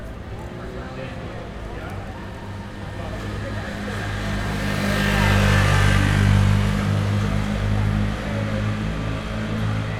UrbanSounds
Environmental
Streetsounds
Noisepollution